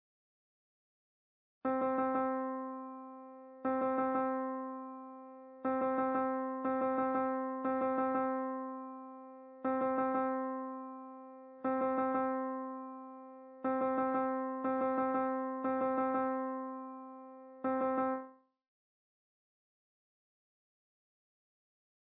Here we flip it around a bit and start with the three note 'lift off' to herald a coming event, very common in the classical styles and in various marching band songs. Dig this idea at 120 beats per minute, a bit of a brighter tempo for high stepping the band on into town.